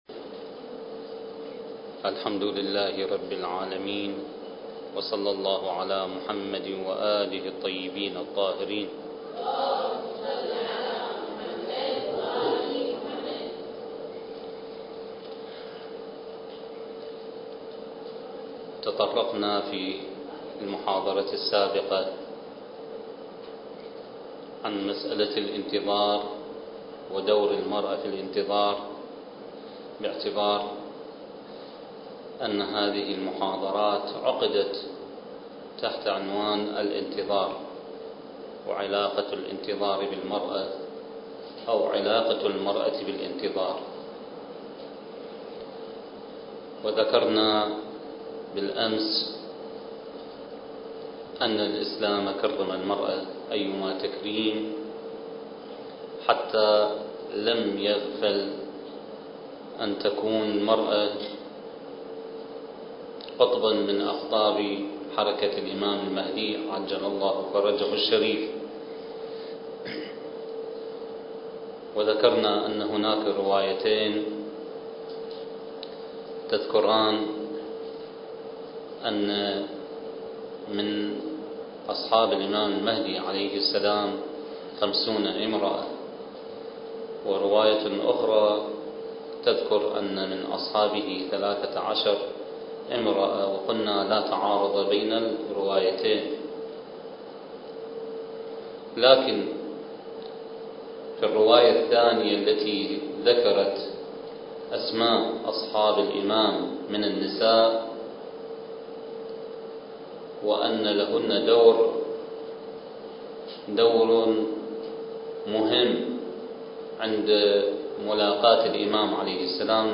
سلسلة: المرأة والانتظار (2) الدورة الثقافية المهدوية للأخوات المؤمنات التي أقامها مركز الدراسات التخصصية في الإمام المهدي (عجّل الله فرجه) تحت شعار (بين صمود الانتظار وبشائر الظهور) التاريخ: 2006